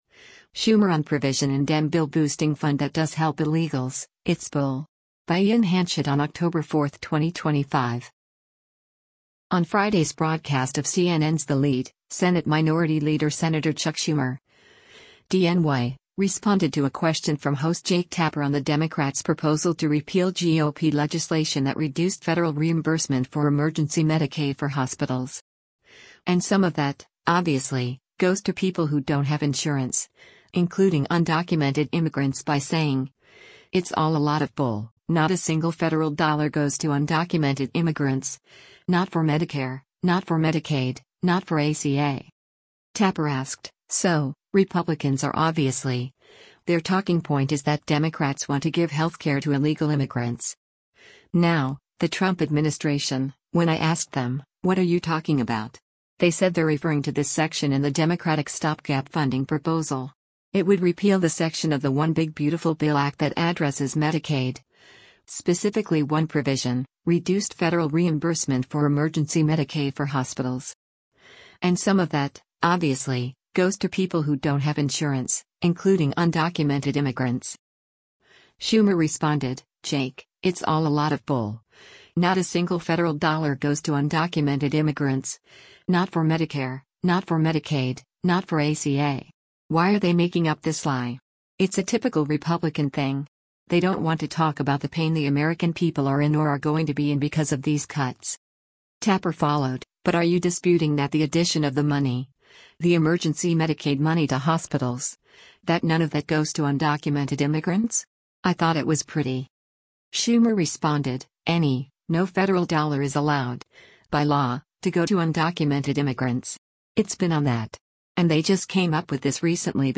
On Friday’s broadcast of CNN’s “The Lead,” Senate Minority Leader Sen. Chuck Schumer (D-NY) responded to a question from host Jake Tapper on the Democrats’ proposal to repeal GOP legislation that “reduced federal reimbursement for emergency Medicaid for hospitals. And some of that, obviously, goes to people who don’t have insurance, including undocumented immigrants” by saying, “it’s all a lot of bull, not a single federal dollar goes to undocumented immigrants, not for Medicare, not for Medicaid, not for ACA.”